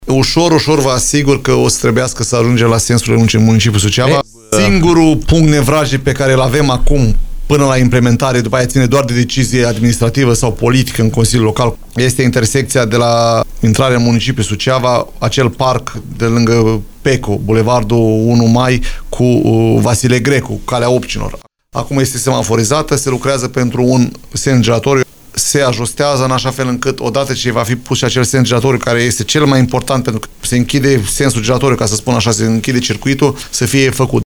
Viceprimarul LUCIAN HARȘOVSCHI a declarat postului nostru de radio că o altă condiție este amenajarea unui sens giratoriu la intersecția bulevardului principal cu Calea Obcinilor, în zona OMV.